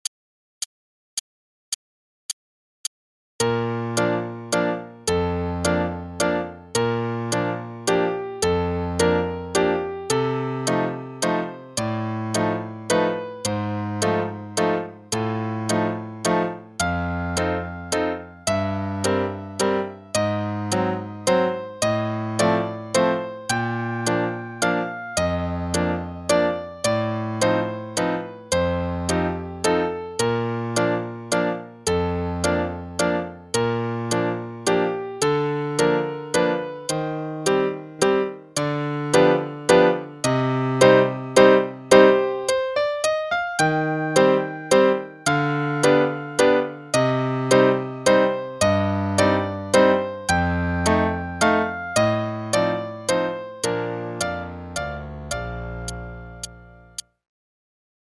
Adult Book 1 (All-in-One): page 110 Sleeping Beauty Waltz (both parts, qn=108)